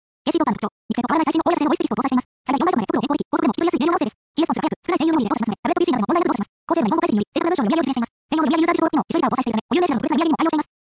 ＫＣトーカーの特徴 肉声と変わらない最新のHOYA社製のVoice Textを搭載しています。 最大４倍速まで速度を変更でき、高速でも聞き取りやすい明瞭な音声です。
ＫＣトーカーのサンプル音声をお聞きください。
４倍速